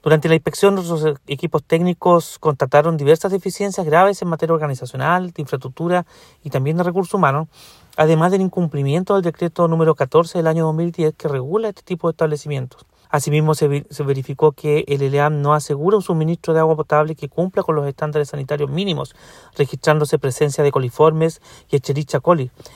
Según informó el seremi de Salud en La Araucanía, Andrés Cuyul, se detectó la presencia de coliformes y otras bacteriass, lo que representa un alto riesgo para la salud de los adultos mayores residentes.